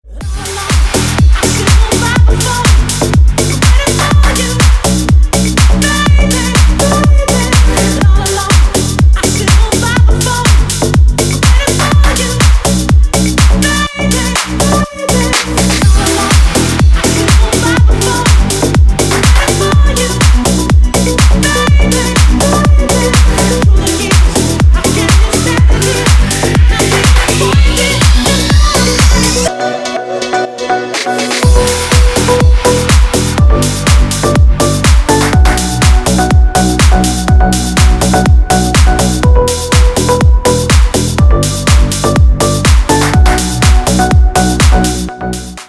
Рингтон Рингтон на Deep House ремикс